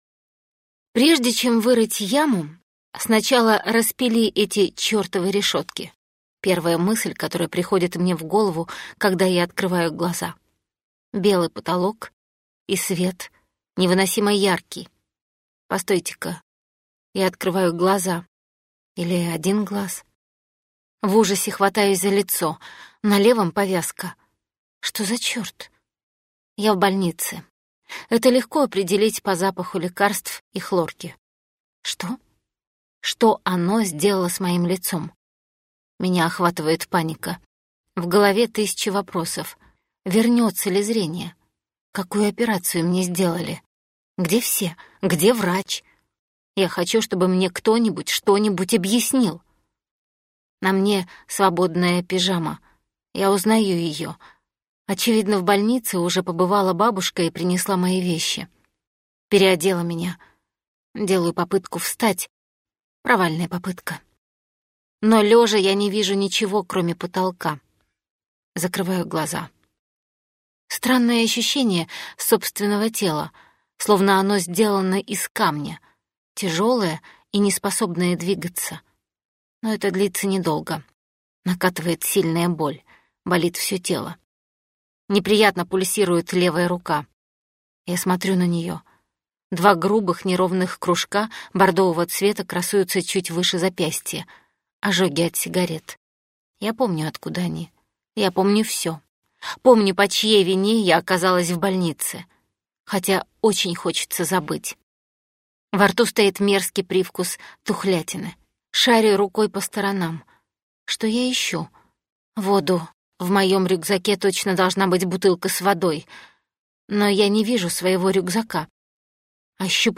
Аудиокнига Мой лучший враг - купить, скачать и слушать онлайн | КнигоПоиск